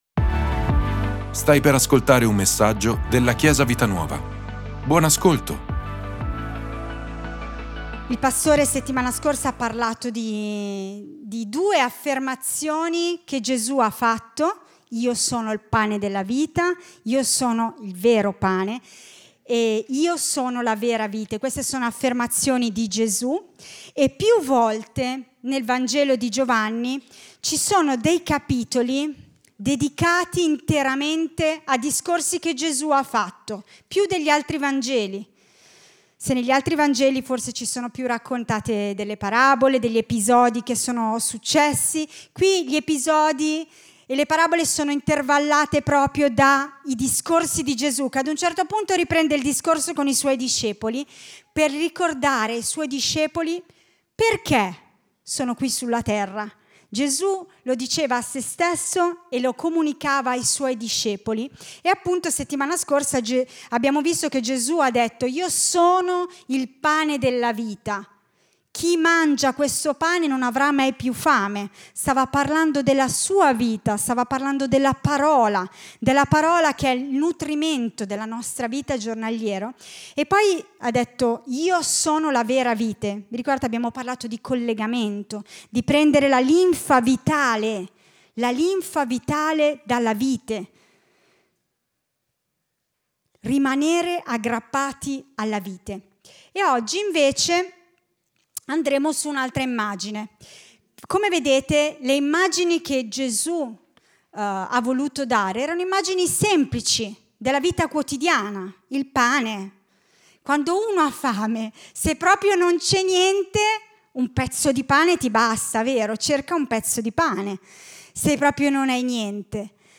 Ascolta la predicazione "Io sono la luce " di Chiesa Vita Nuova.